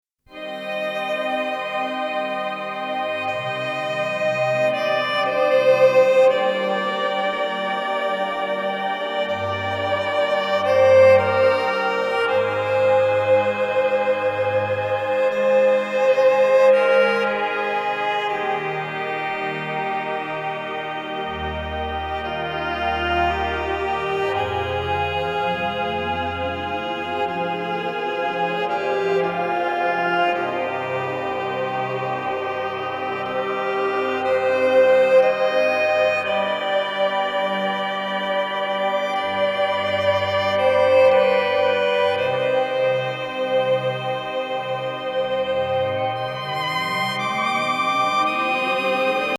睡眠用ヒーリング・ミュージック!
ヴァイオリンと電子音。幽玄シンセが交差する和アンビエント作!